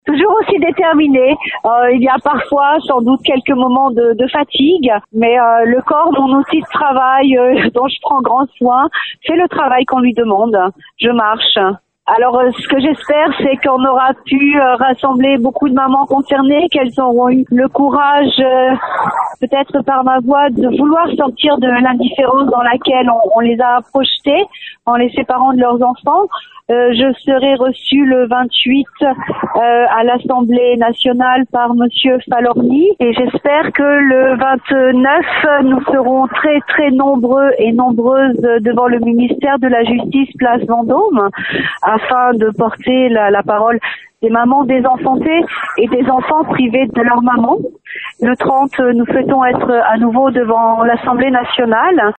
Contactée par téléphone, nous lui avons demandé dans quel état d’esprit elle se trouve :